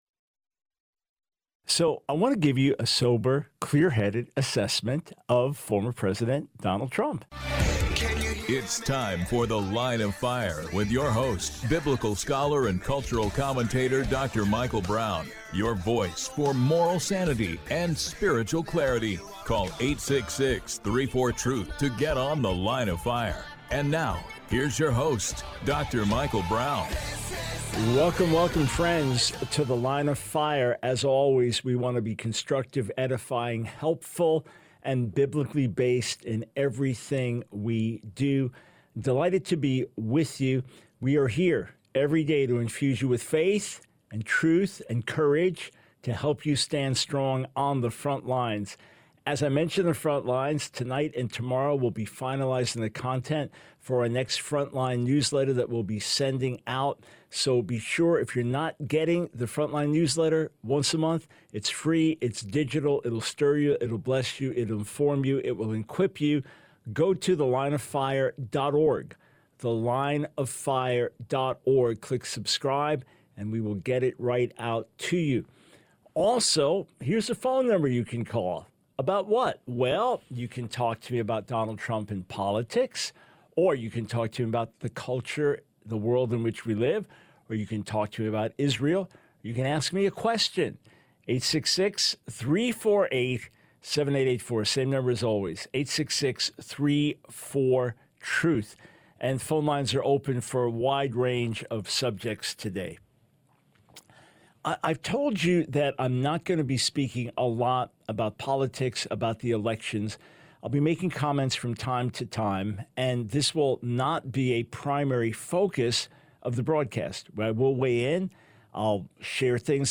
The Line of Fire Radio Broadcast for 05/14/24.